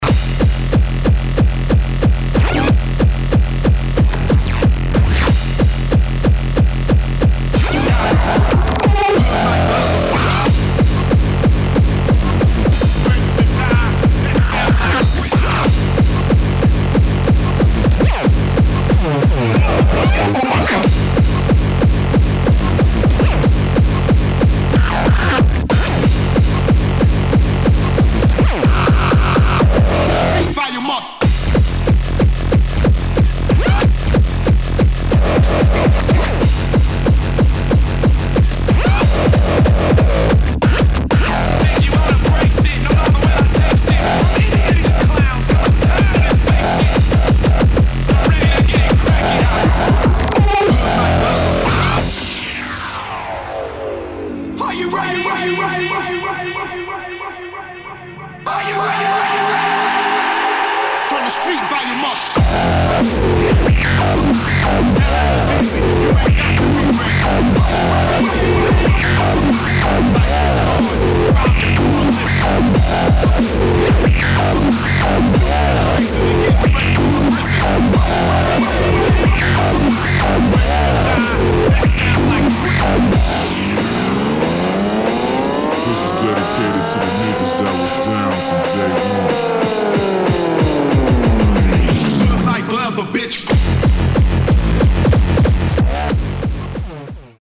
[ HARDCORE / GABBA / INDUSTRIAL ]